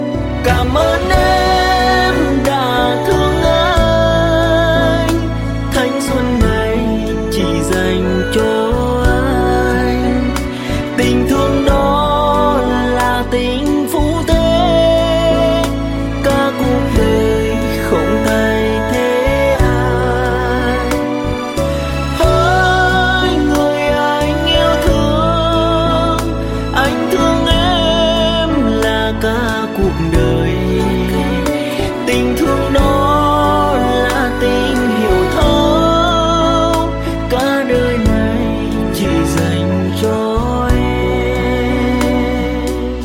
Nhạc Trẻ